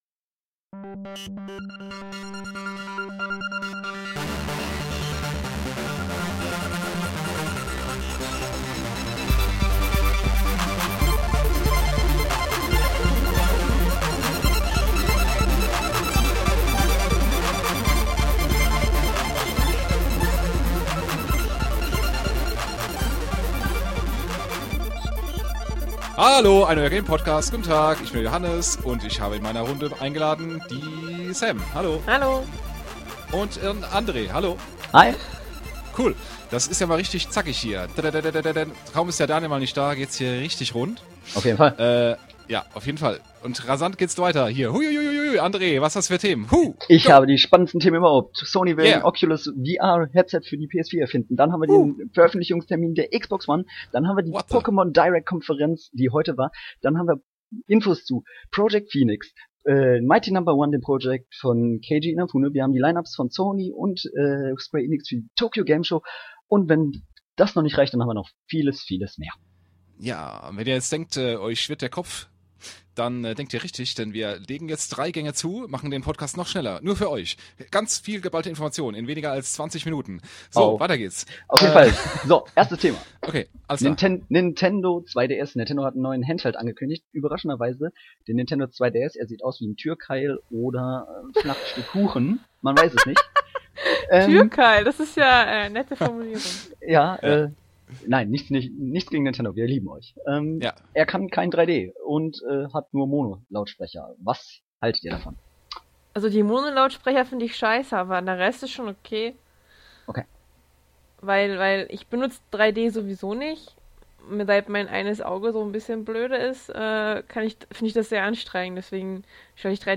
Richtig, sie moderieren in maximaler Geschwindigkeit!